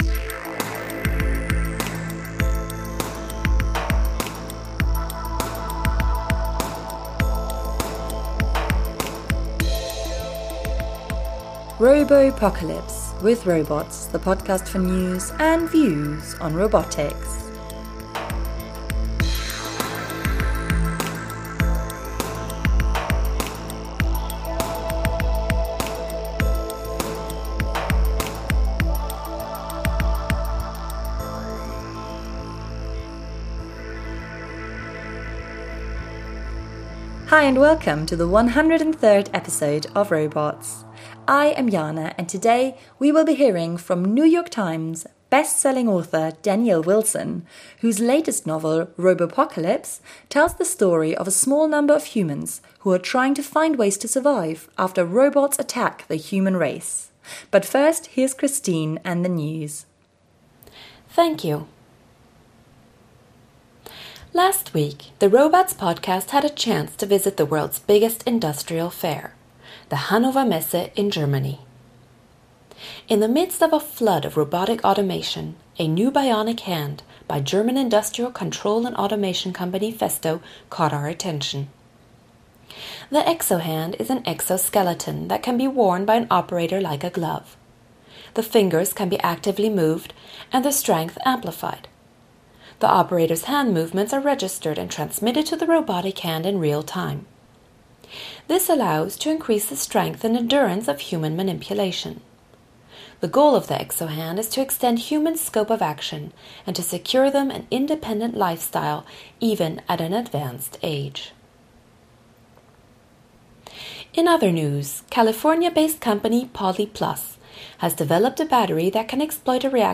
In this interview he gives us an update on his more recent books since we talked in 2007 .